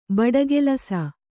♪ baḍagelasa